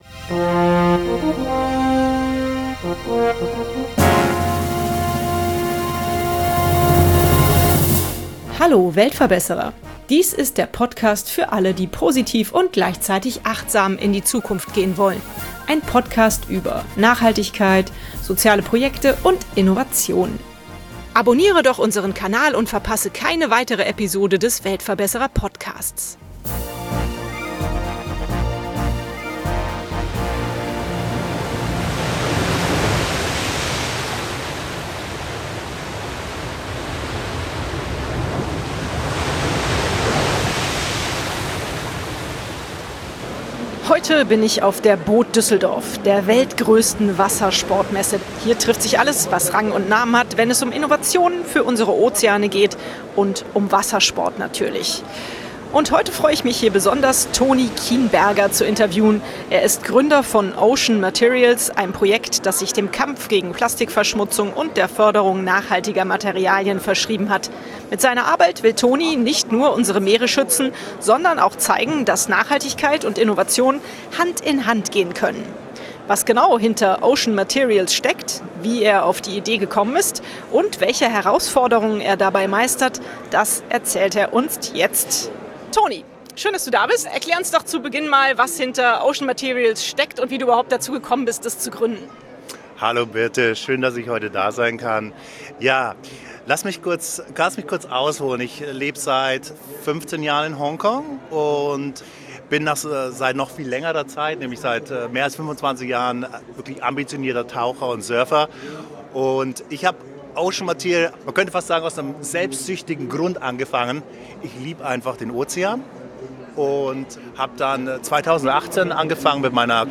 Heute bin ich auf der boot Düsseldorf, der größten Wassersportmesse der Welt. Hier trifft sich alles, was Rang und Namen hat, wenn es um Innovationen für unsere Ozeane geht.